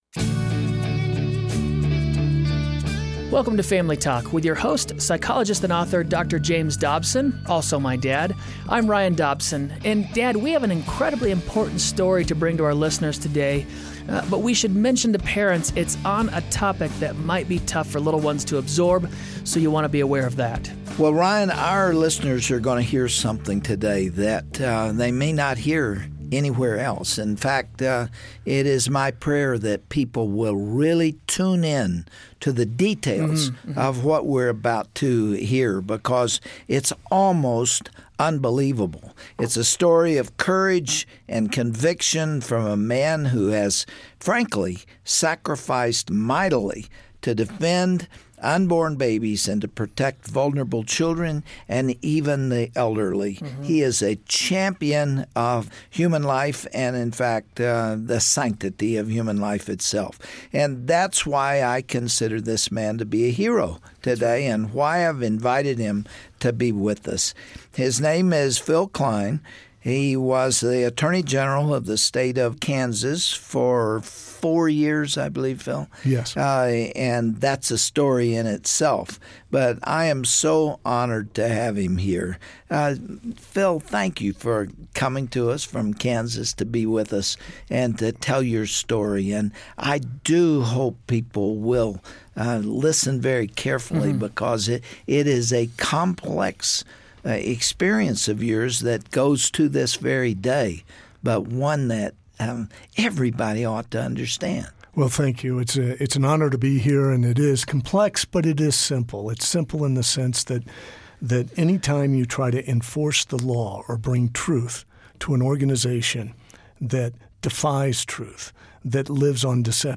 Today you'll get an unvarnished look at some of the criminal activity in this pro-abortion organization. Studio guest Phill Kline, former Attorney General of Kansas, reveals details about how some very powerful people worked overtime to keep the truth a secret.